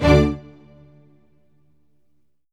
Orchestral Hits
ORCHHIT D3-L.wav